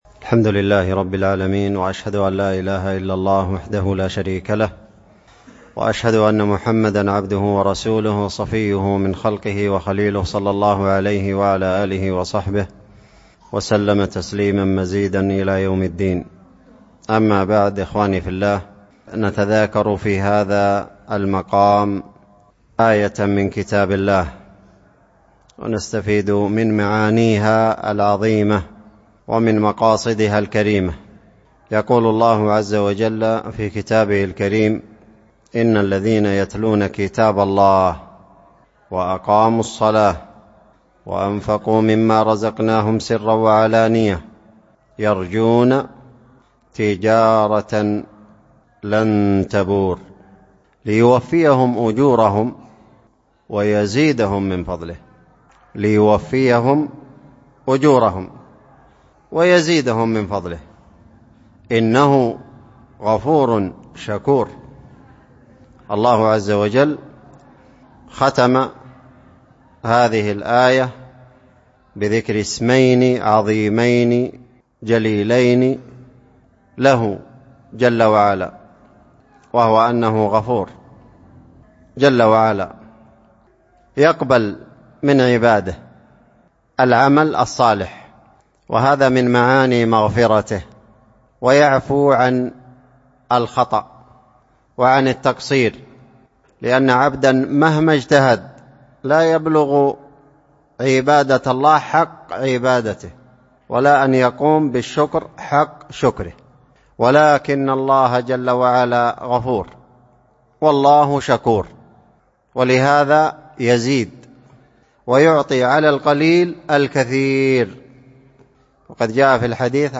ألقيت في الشحير من ساحل حضرموت .
كلمة بعد العصر في الشحير.mp3